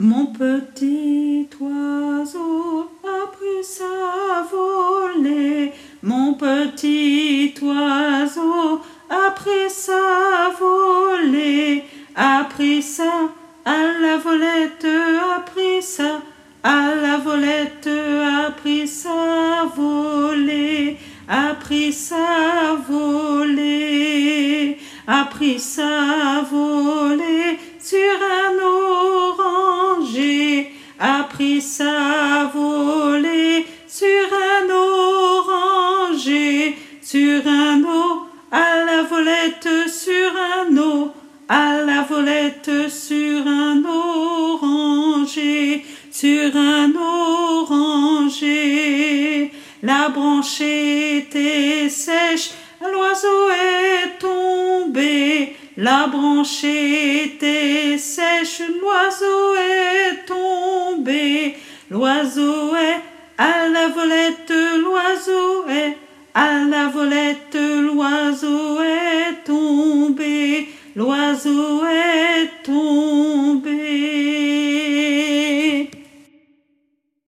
Alto